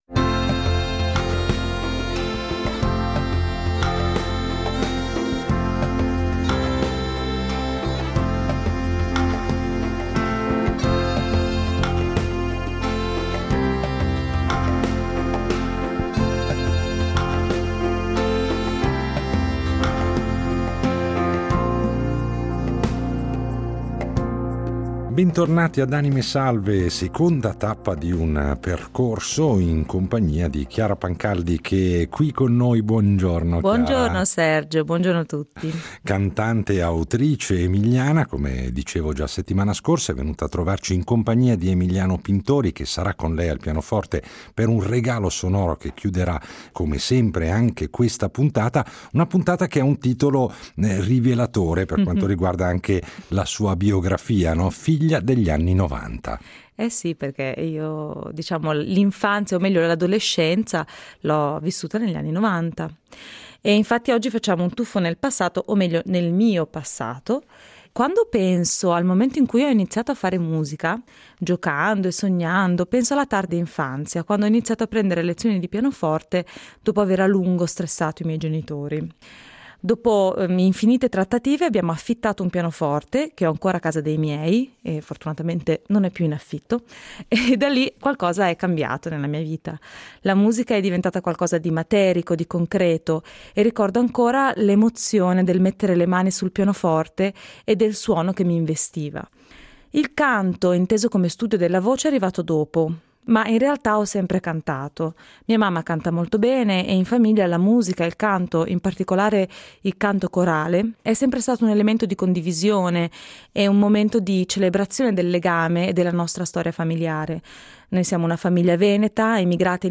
canto jazz
che ascolteremo in versioni più intime